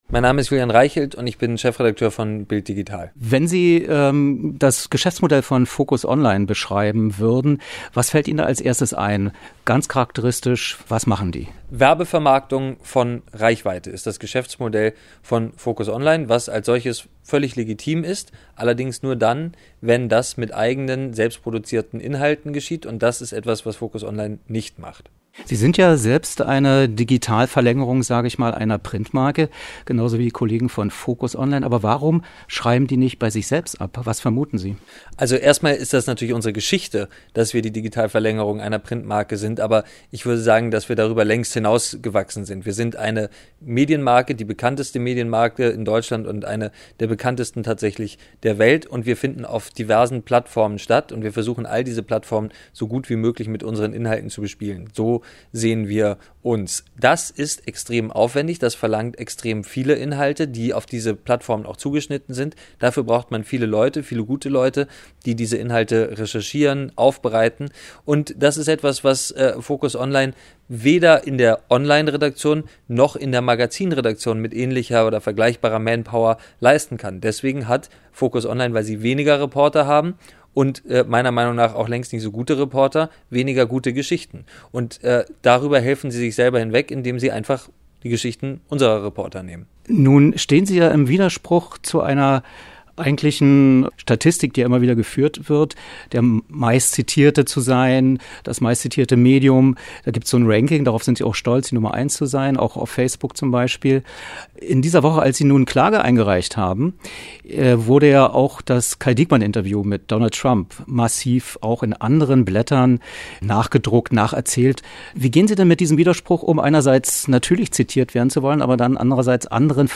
Wer: Julian Reichelt, Chefredakteur BILD Digital
Was: Interview zur Klage gegen FOCUS ONLINE
Wo: Berlin, Redaktionsgebäude, Axel-Springer-Straße 65